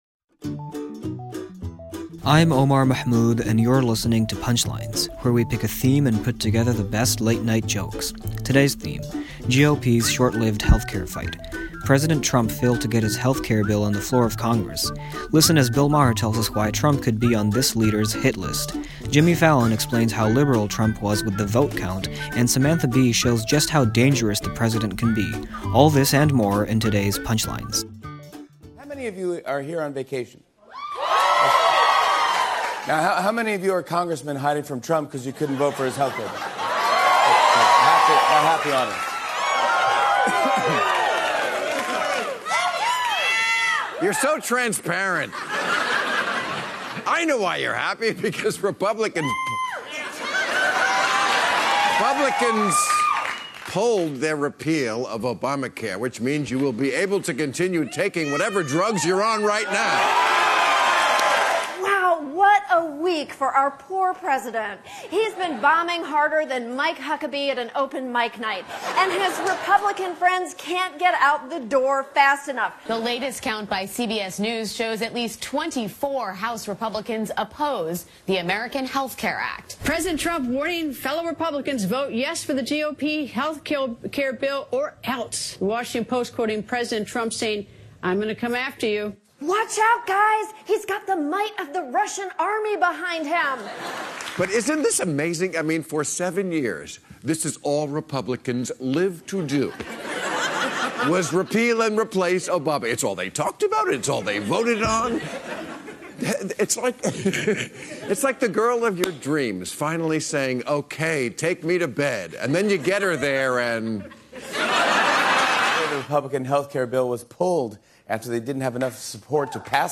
The late-night comics on Trump decision to pull bill.